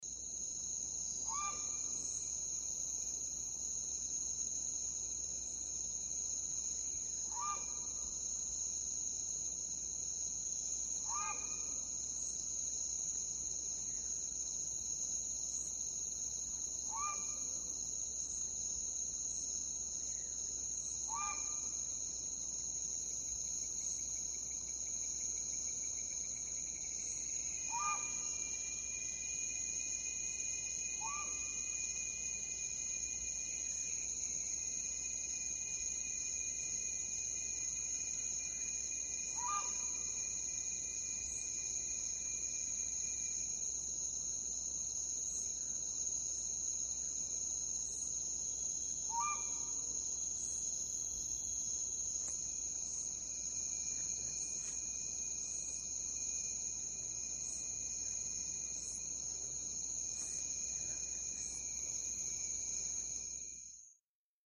Guatemalan jungle with cicadas & Cocolito bird, El Mirador